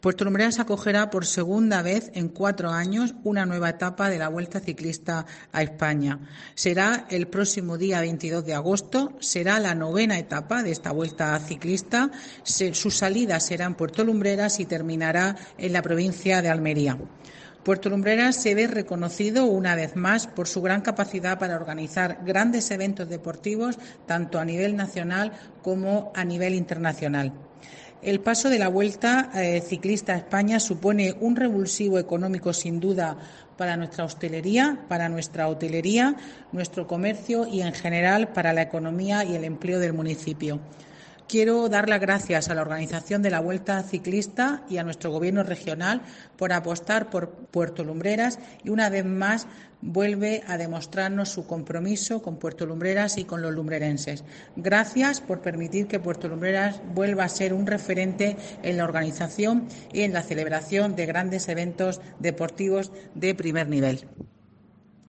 María de los Ángeles Túnez, alcaldesa de Puerto Lumbreras